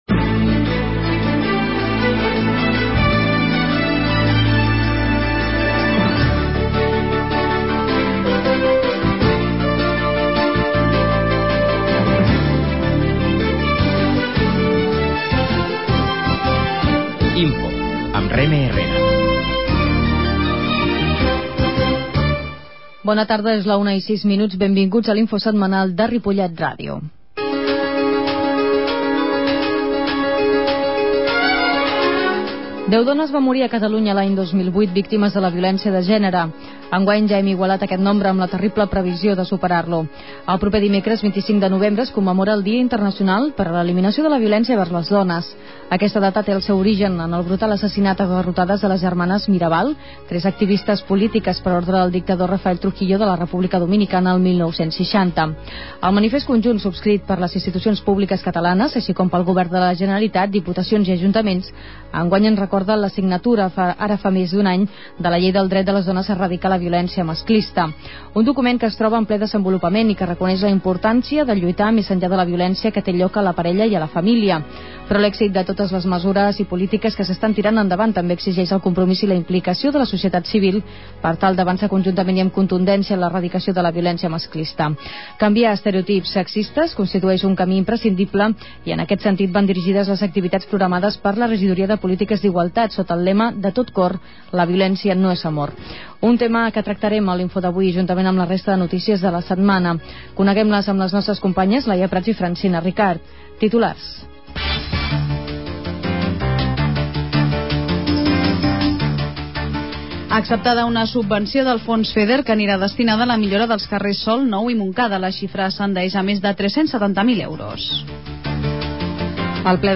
La qualitat de so ha estat redu�da per tal d'agilitzar la seva desc�rrega.